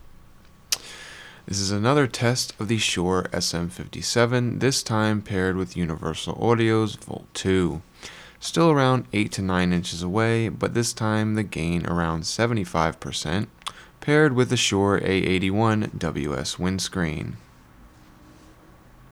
The SC8 has this subtle crackly character and sounds a bit distorted vs. the very clean signal coming out of the Volt 2.
Universal_Audio_Volt_2_SM57_FL_Studio_Trimmed.wav